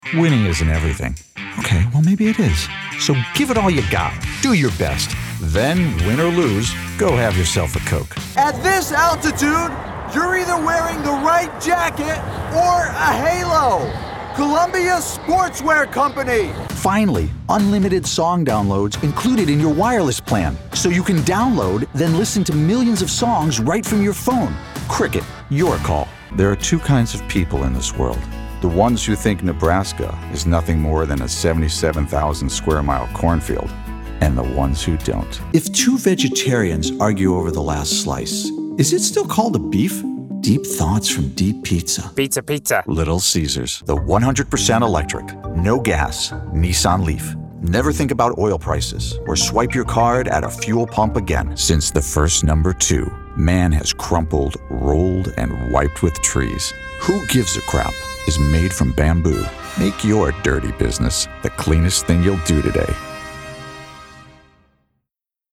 Commercial Demo
Middle Aged
My voice has been described as warm, trustworthy, and confident.